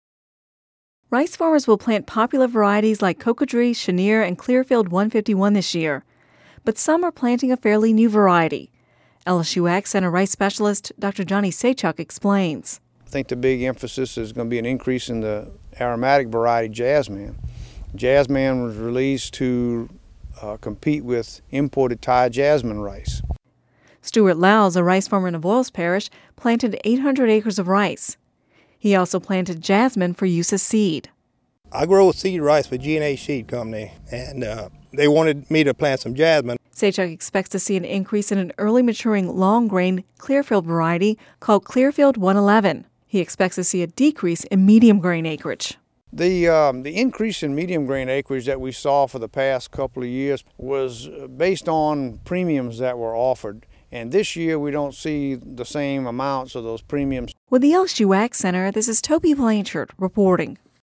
(Radio News 05/03/10) Rice farmers will plant popular varieties like Cocodrie, Chenier and Clearfield 151 this year, but some are planting a fairly new variety developed by the LSU AgCenter. It's called Jazzman.